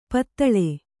♪ pattaḷe